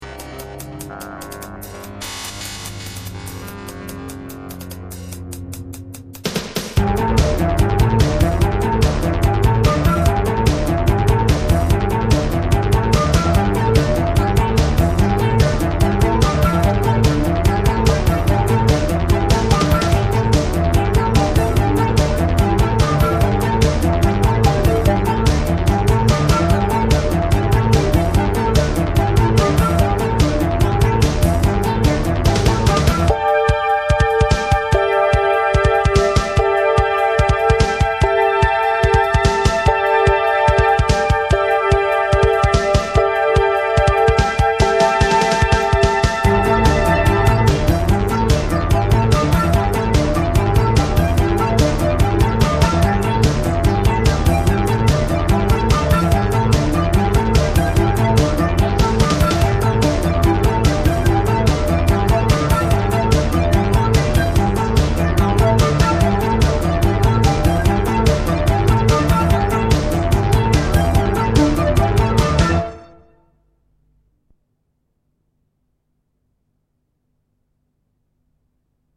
Real Techno-Pop.